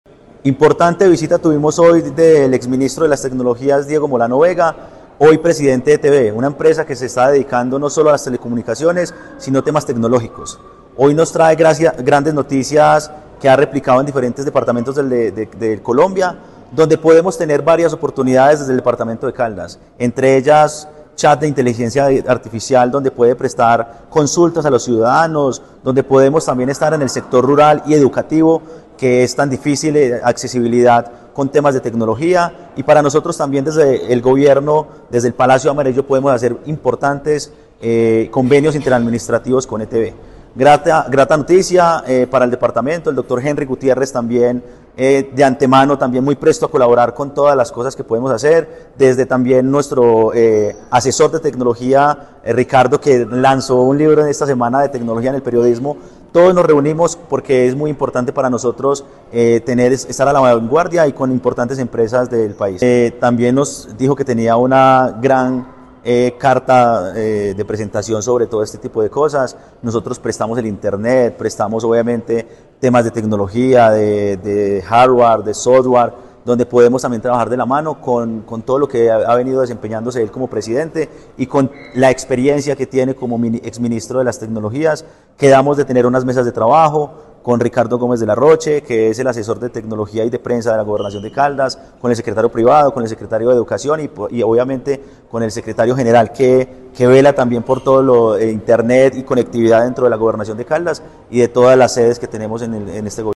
Juan Manuel Marín López, secretario General de la Gobernación de Caldas